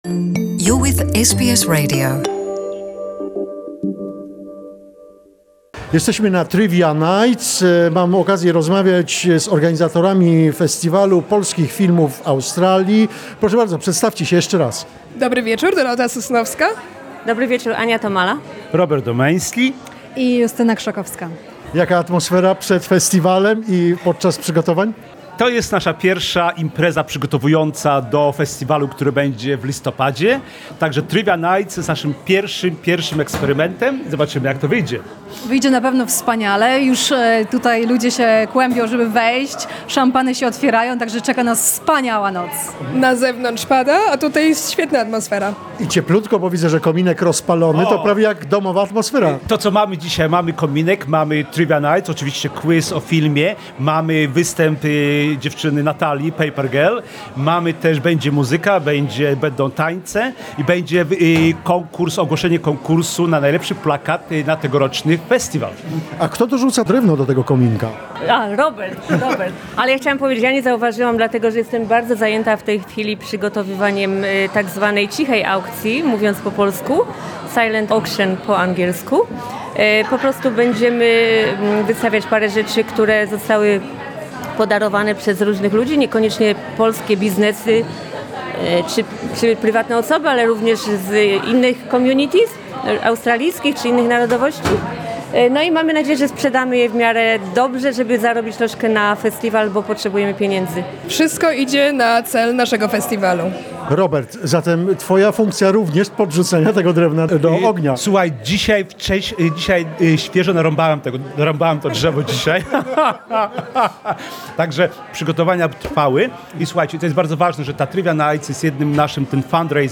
Podczas spotkania ogłoszono zwycięzcę konkursu na najlepszy projekt plakatu Festiwalu Polskich Filmów w Australii. Oto relacja z tej imprezy...